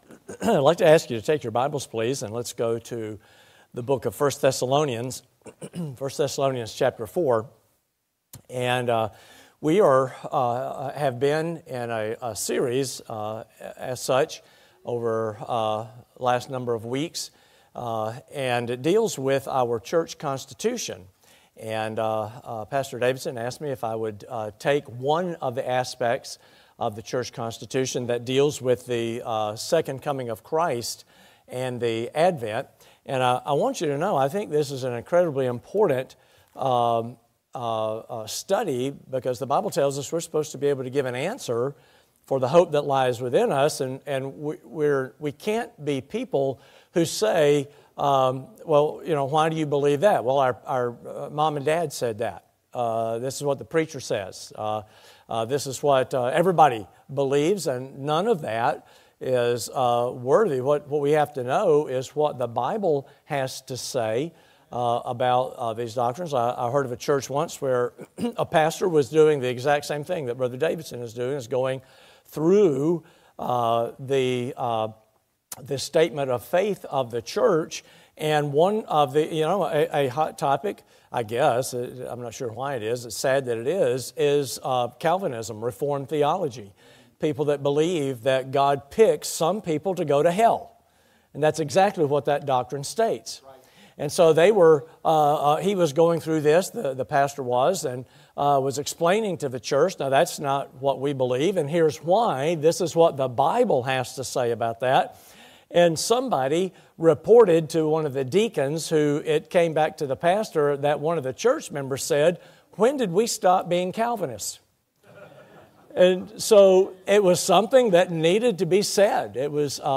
A Look Through Our Church Constitution Passage: I Thes. 4:13-18 Service Type: Sunday PM Topics